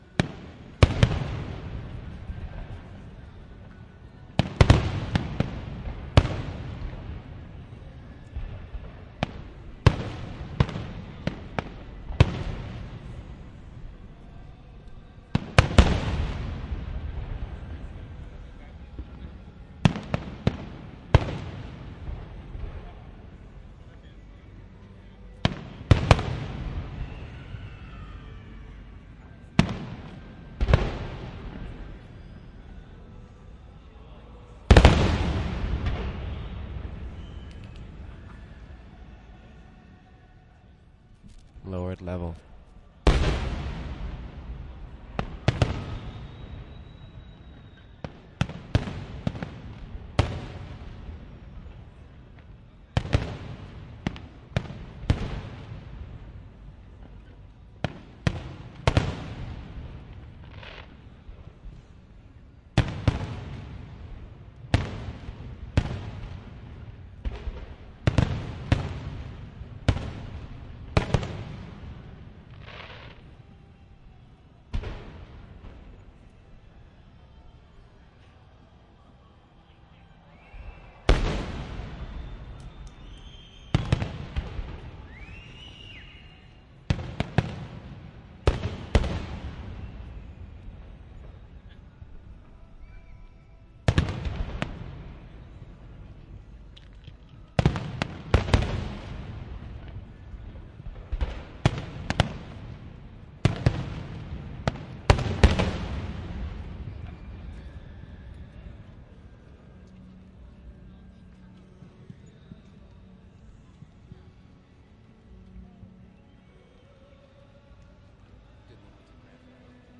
烟花 " 关闭 Popsizzle july408
描述：单一的烟花射击，有咝咝声。
标签： 场记录 焰火 流行 嘶嘶
声道立体声